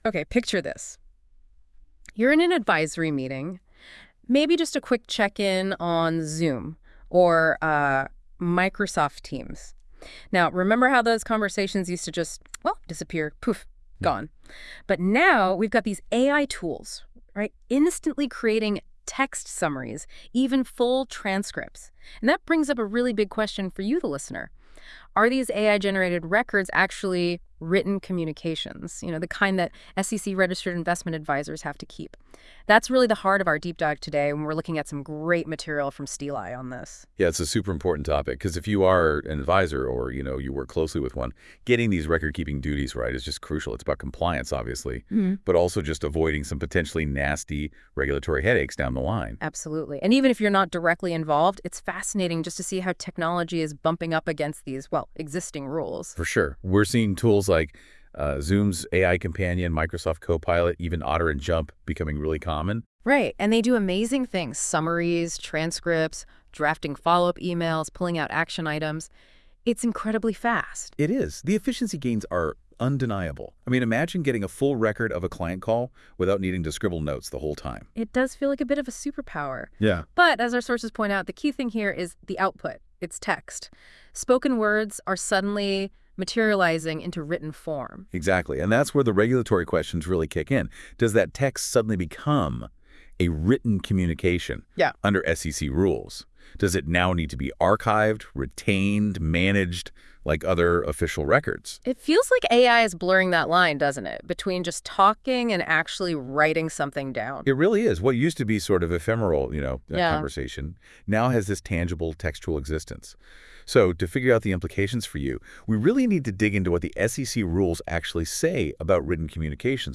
Take this blog on the go with our AI-generated podcast by listening here.